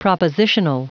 Prononciation du mot propositional en anglais (fichier audio)
Prononciation du mot : propositional